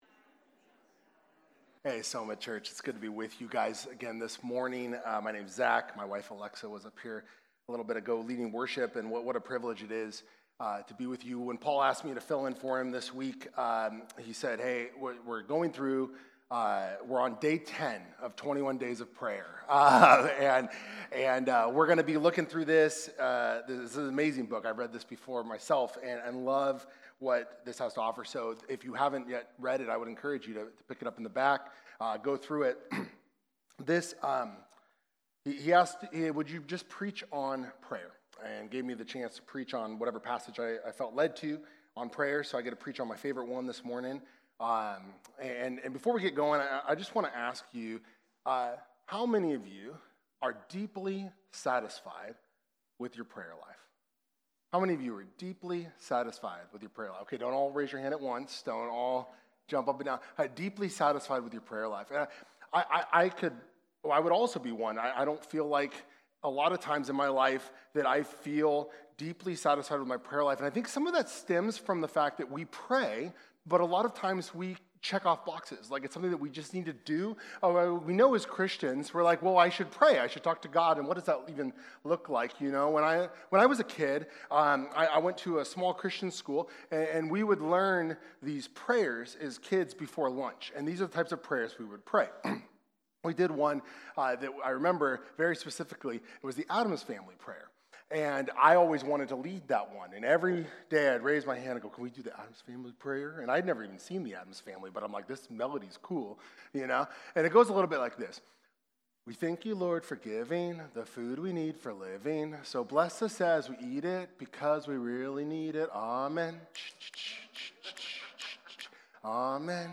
Sermons
Sermons from SOMA Church Community: Santa Rosa, CA